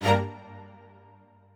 admin-leaf-alice-in-misanth…/strings34_2_000.ogg